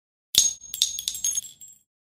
Download Free Bullet Sound Effects | Gfx Sounds
Shell-casings-fall-on-concrete.mp3